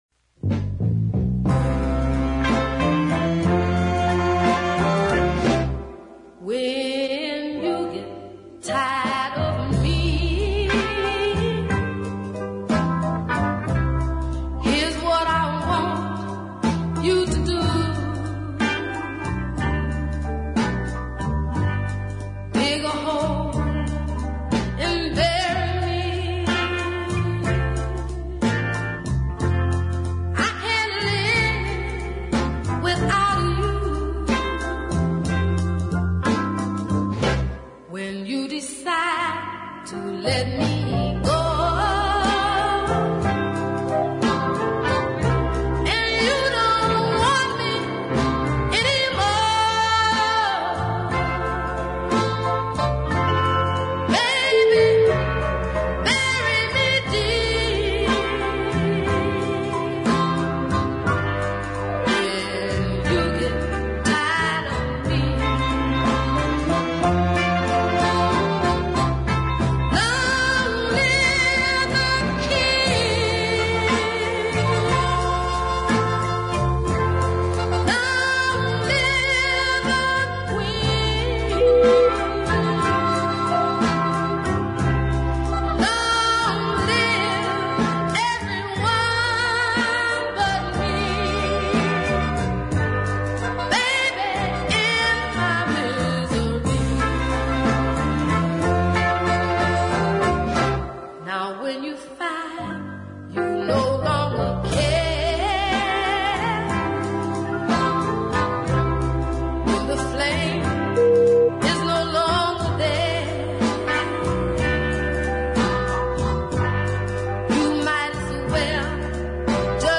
strong confident tone
backed by a fine arrangement – love those muted trumpets.